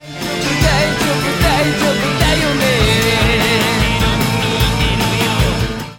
……おおおぉおぉッ！ 消えたッ！ 思った以上にシッカリと消えたぞッ！
性質上、モノラルになってしまうので、音の広がりはなくなってしまうが、コモった音になった感じはしない。